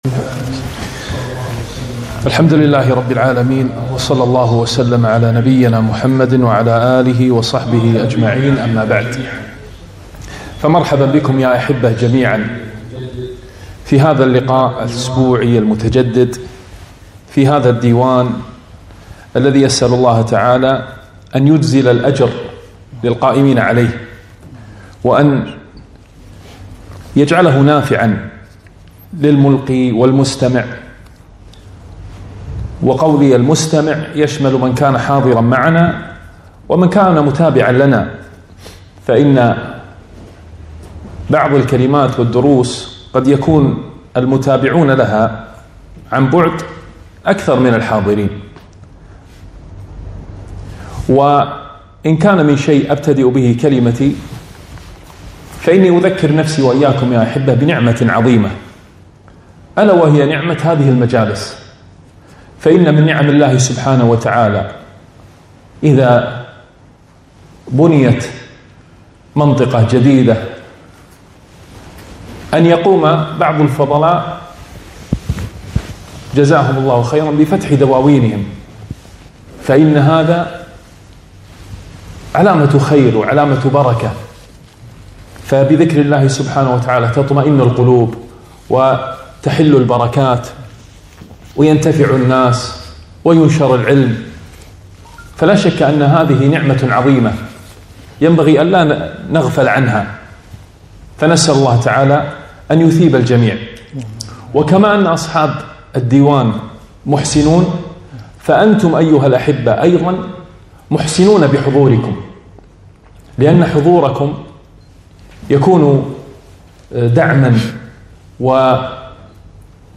محاضرة - من أمثال القرآن - دروس الكويت
محاضرة - من أمثال القرآن